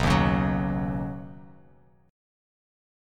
Badd9 chord